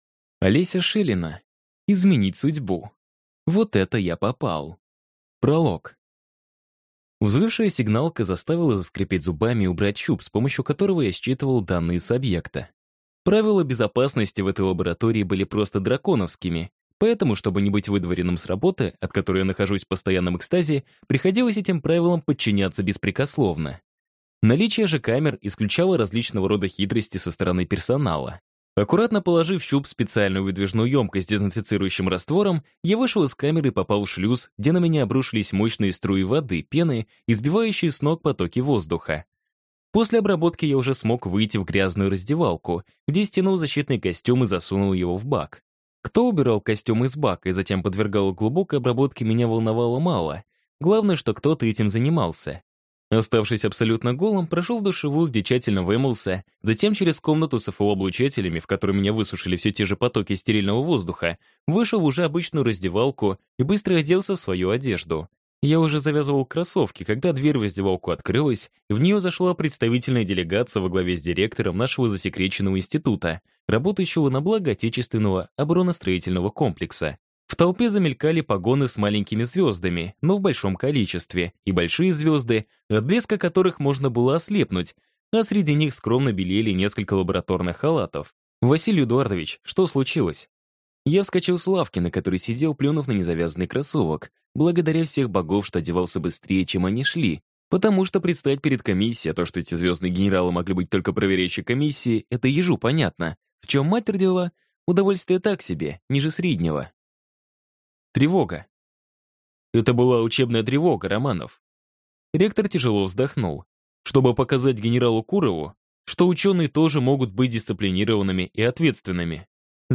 Аудиокнига Изменить судьбу. Вот это я попал | Библиотека аудиокниг